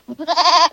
goat01.ogg